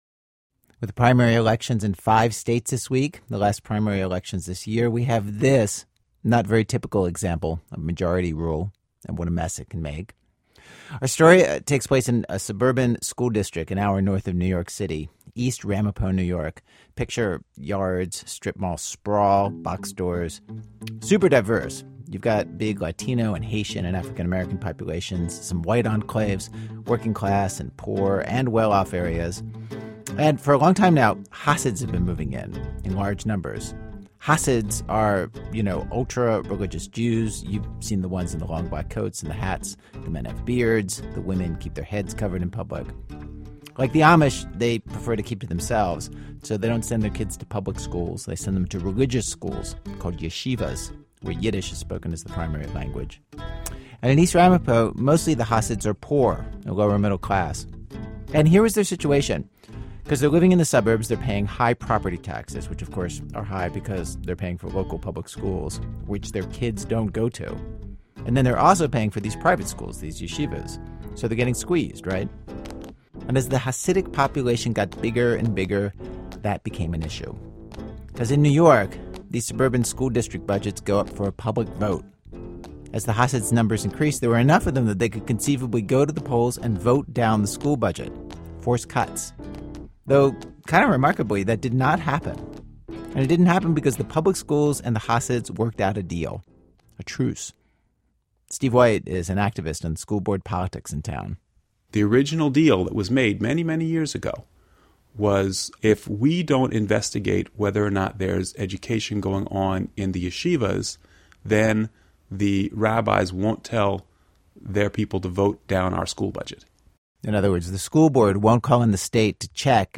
BEEPED VERSION.
534_bleeped.mp3